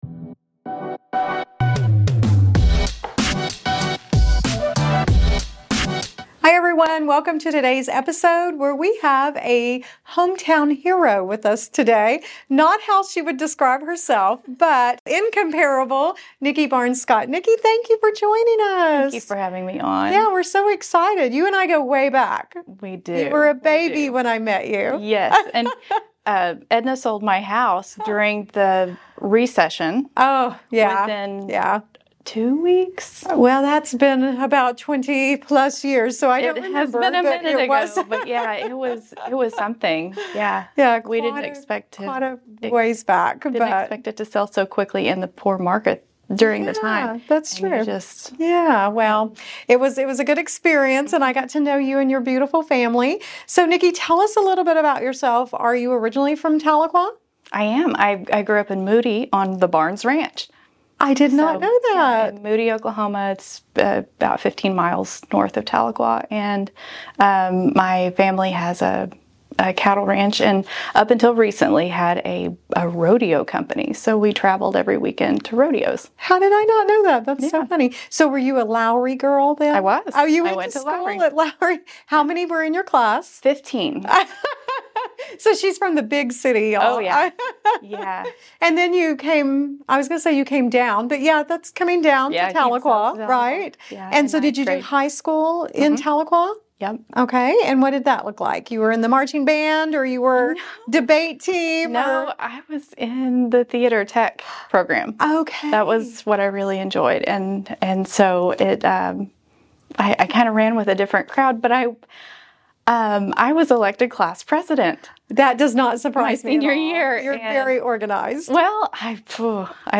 If you're interested in real estate, community building, or looking to support Habitat's work in Tahlequah, you won’t want to miss this conversation!